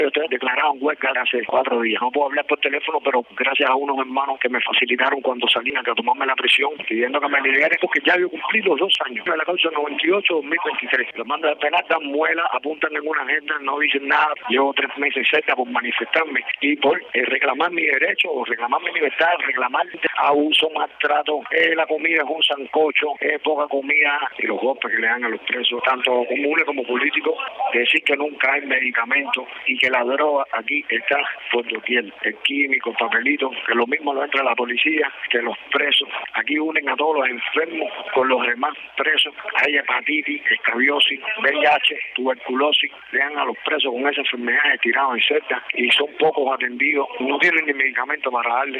En una llamada telefónica desde la cárcel de máxima seguridad de La Habana, Combinado del Este
Declaraciones